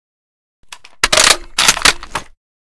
3098b9f051 Divergent / mods / M24 and M98B Reanimation / gamedata / sounds / weapons / librarian_m98b / unjam.ogg 16 KiB (Stored with Git LFS) Raw History
unjam.ogg